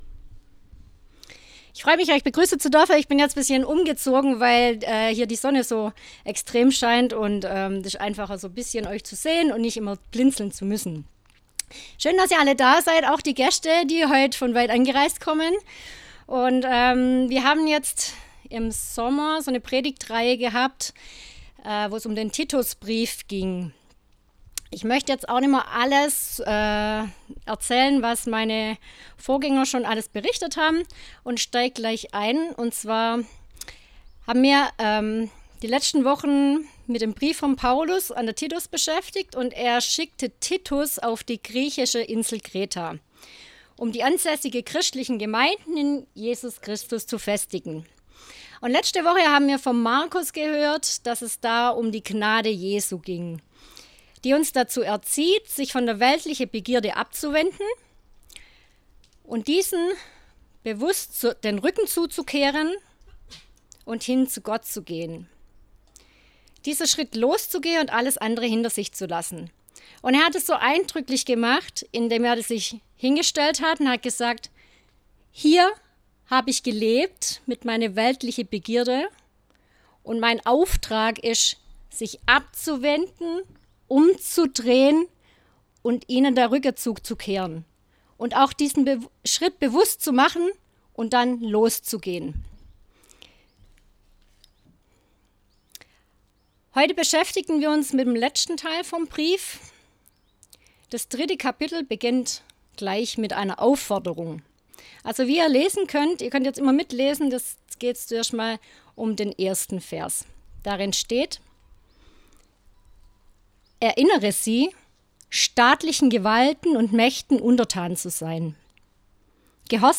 Predigt 01.09.2024 - SV Langenau